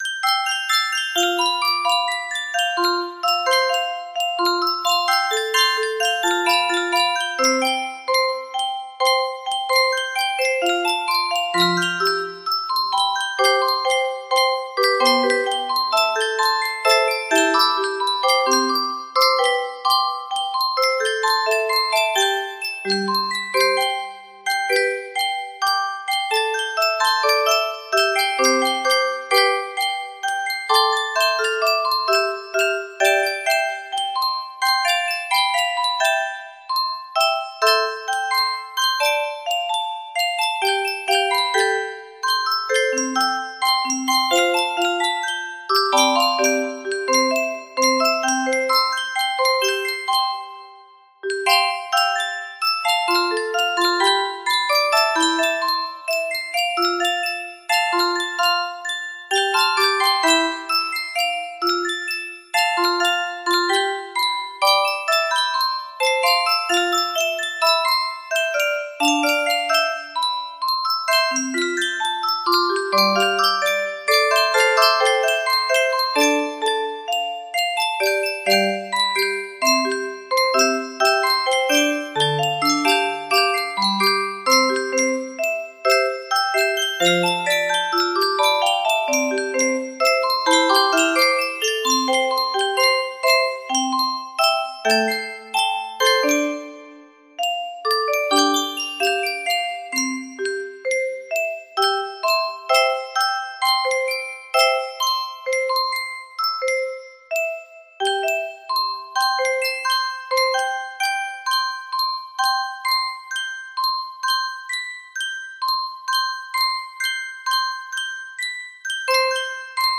Full range 60
No reds Proper Tempo